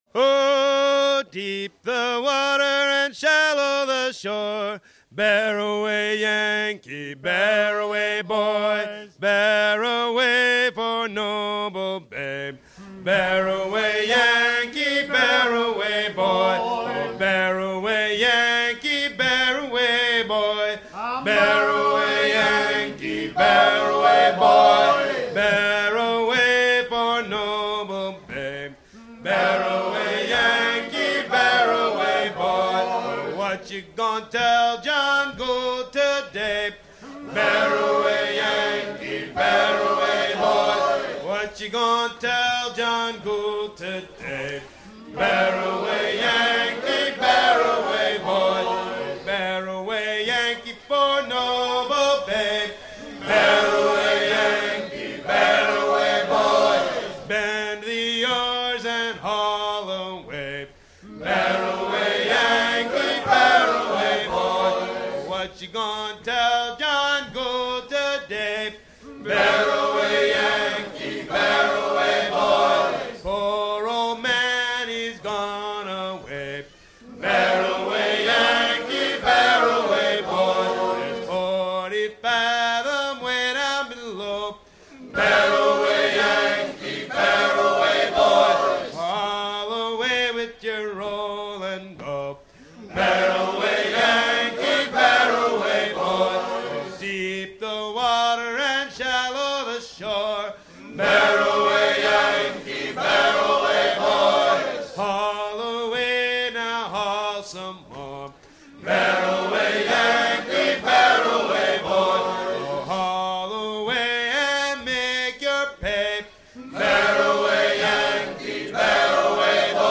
gestuel : à ramer
circonstance : maritimes
Pièce musicale éditée